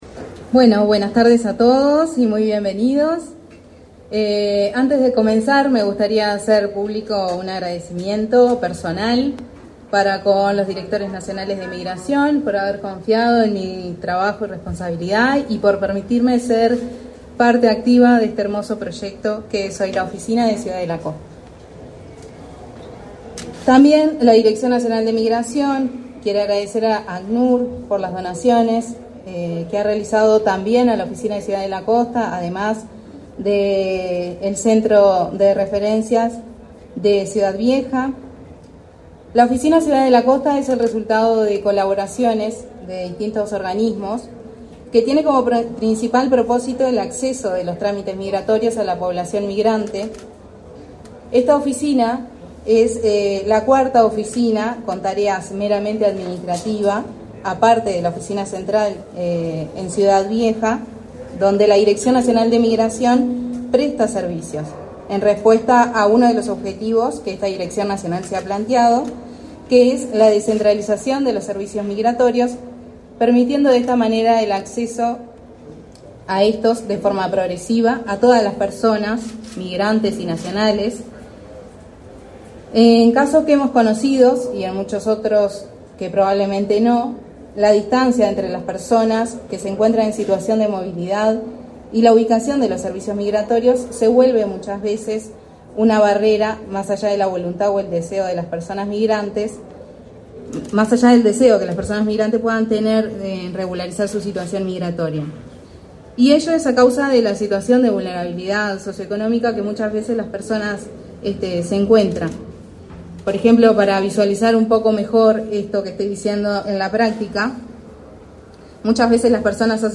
Palabras de la jefa de Migraciones, Melina Martínez
Palabras de la jefa de Migraciones, Melina Martínez 13/12/2024 Compartir Facebook X Copiar enlace WhatsApp LinkedIn Con la presencia del ministro del Interior, Nicolás Martinelli, fue inaugurada, este 13 de diciembre, la oficina de la Dirección Nacional de Migración, en Costa Urbana Shopping.